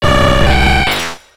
Cri d'Artikodin dans Pokémon X et Y.